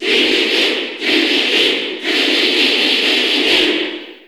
Crowd cheers (SSBU) You cannot overwrite this file.
King_Dedede_Cheer_English_SSB4_SSBU.ogg